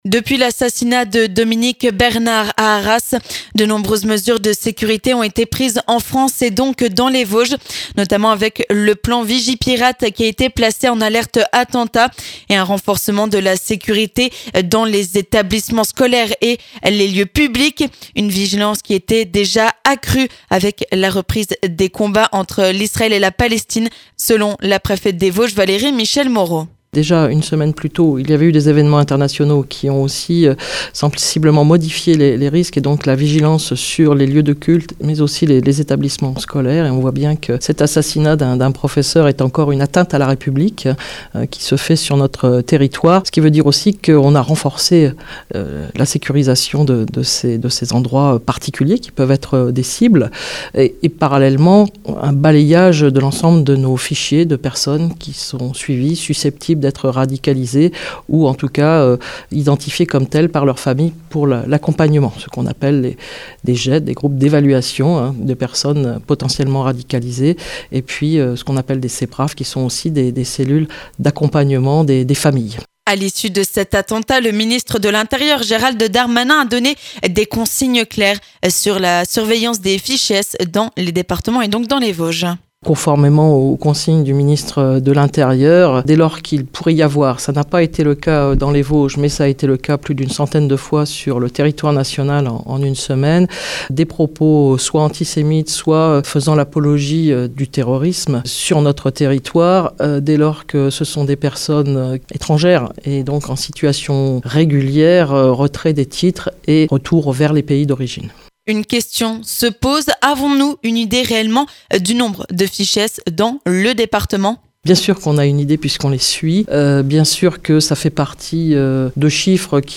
Ce mardi, la Préfète des Vosges a tenu un point presse sur la sécurité accrue dans les Vosges suite à l'assassinat de Dominique Bernard dans un attentat à Arras vendredi matin.
Contrôle des sacs, de l'identité ou encore suivi des fichés S dans le département. On fait le point avec Valérie Michel-Moreaux, Préfète des Vosges.